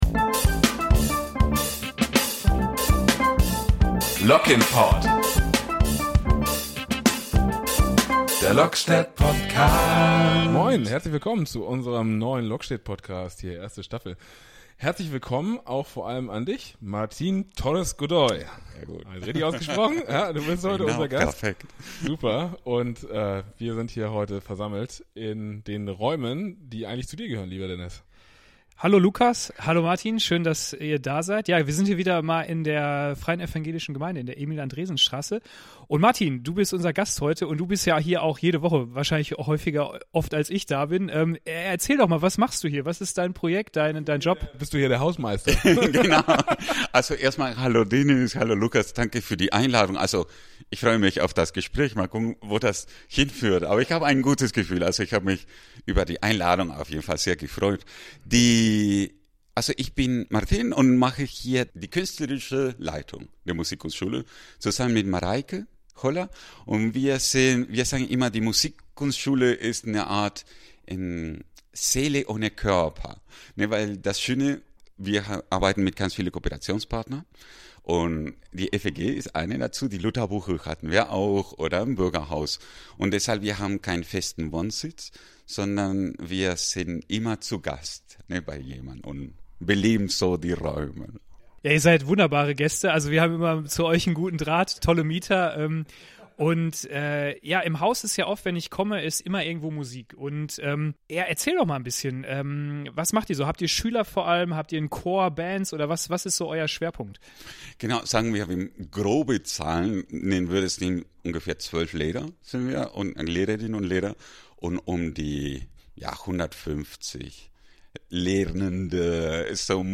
Ein fröhliches Gespräch